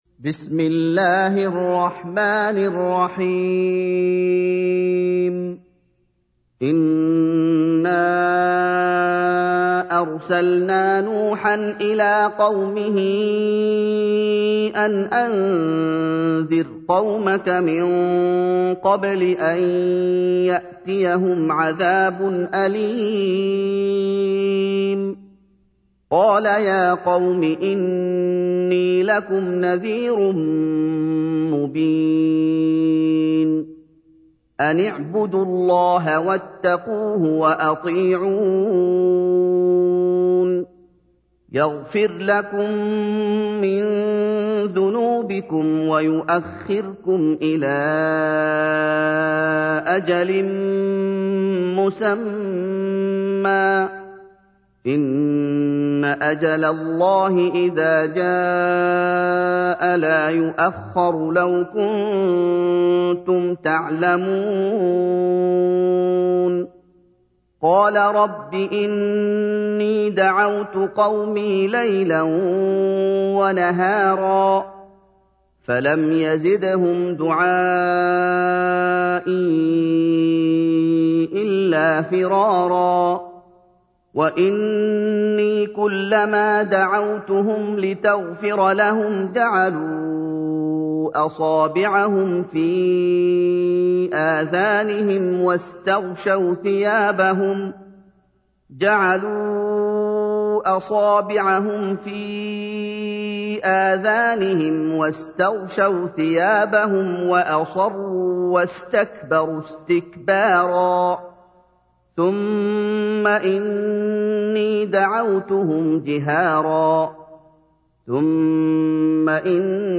تحميل : 71. سورة نوح / القارئ محمد أيوب / القرآن الكريم / موقع يا حسين